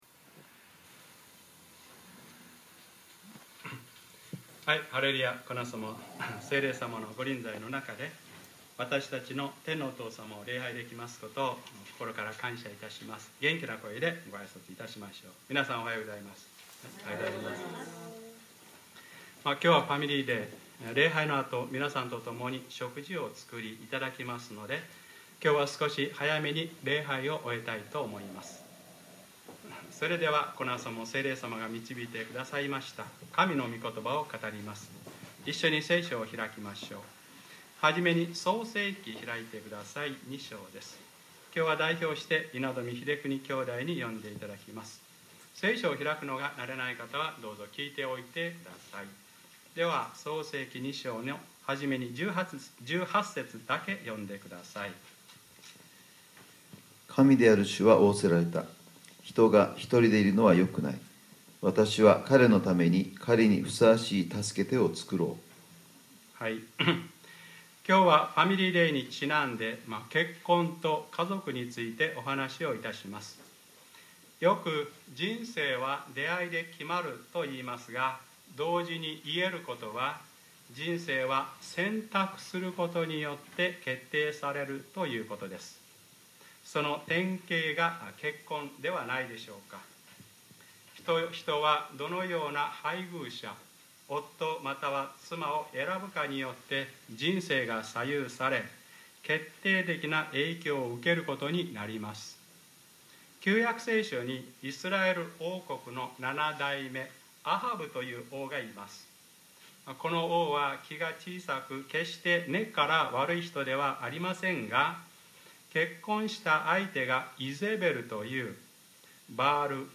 2012年6月17日(日）礼拝説教 『私と私の家とは主に仕えます』